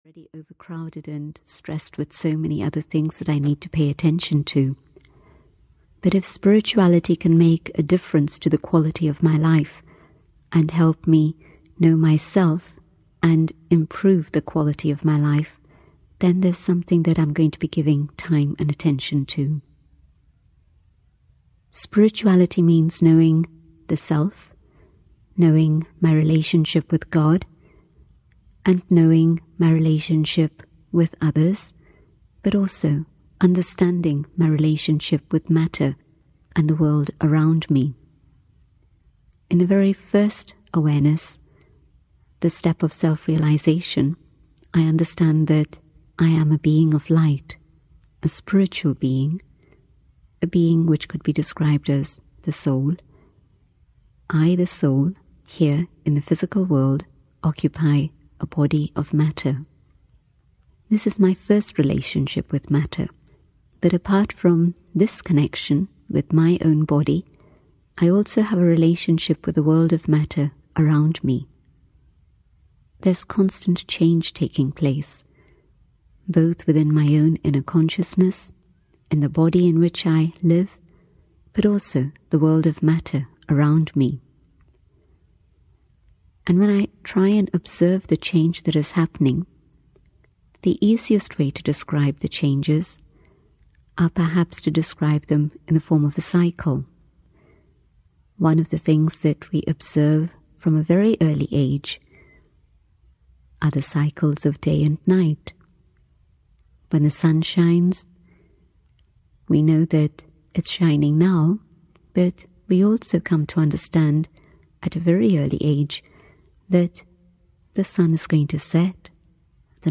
Cycle of Change (EN) audiokniha
Ukázka z knihy